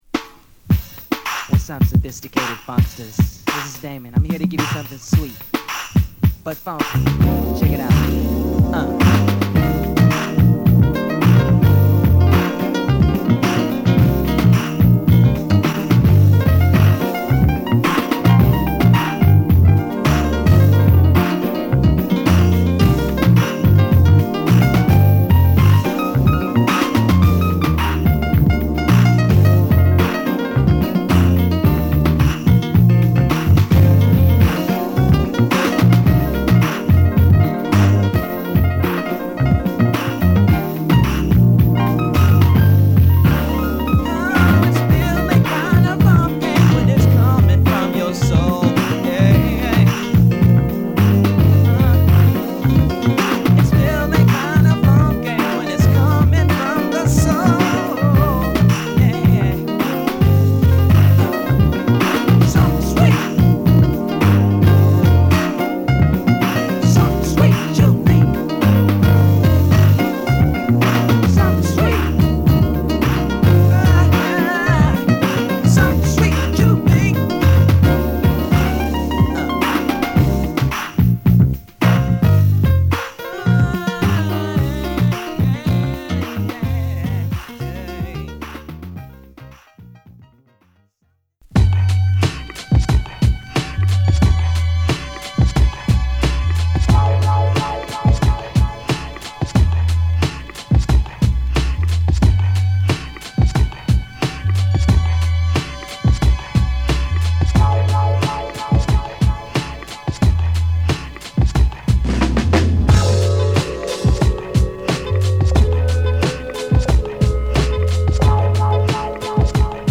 ピアノをメインに使ったモダンファンク